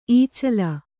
♪ īcala